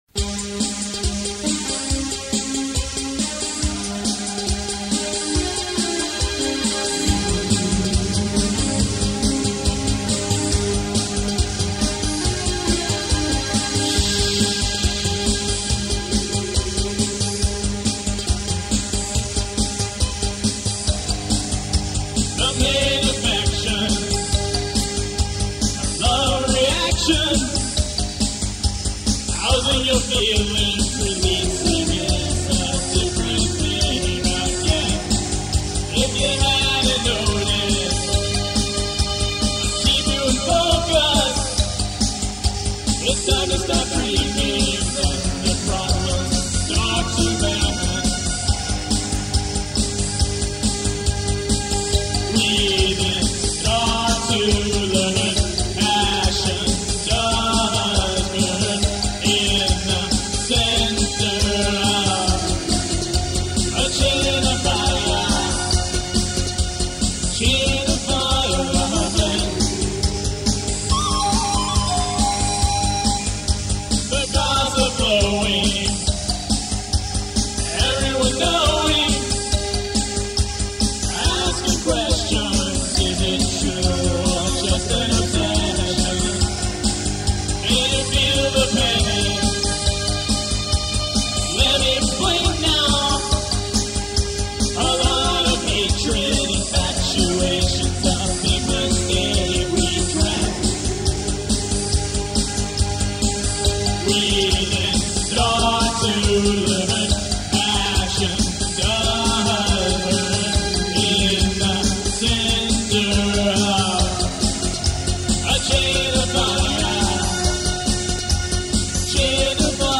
(These Are Early Demos)